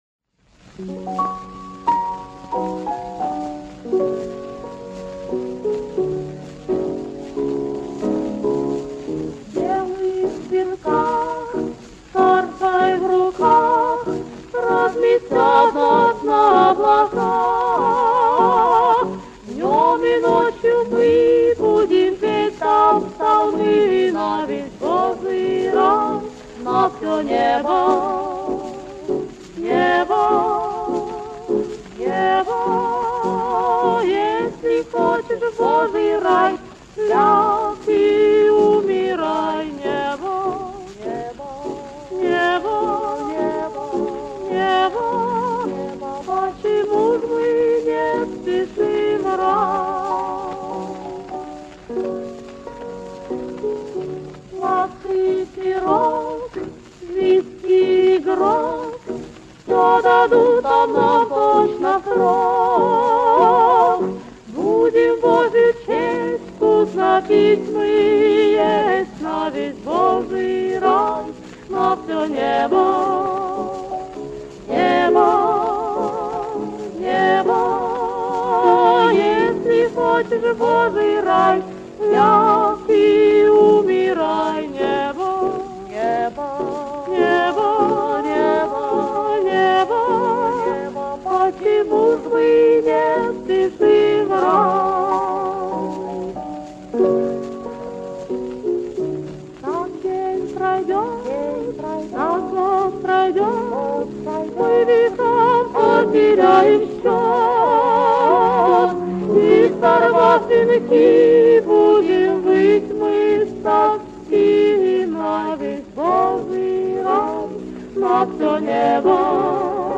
негритянская народная песня
(вокальный дуэт)